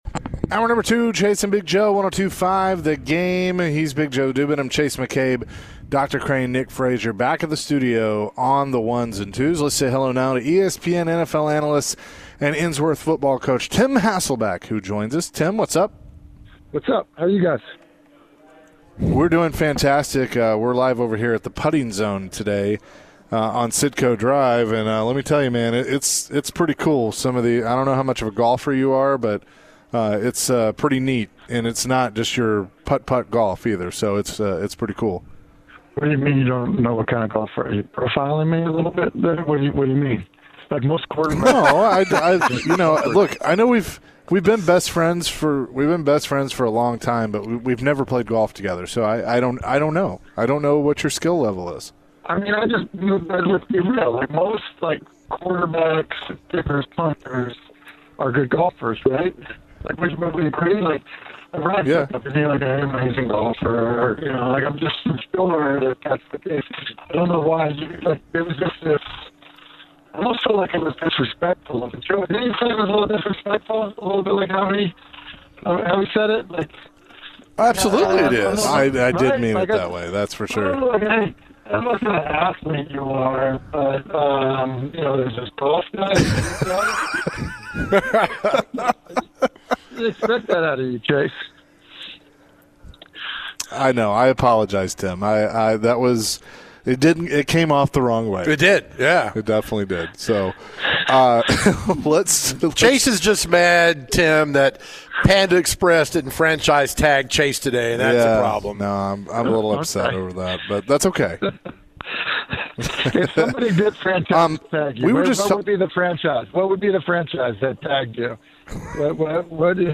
Later in the conversation, the guys chatted more about the recent NFL news.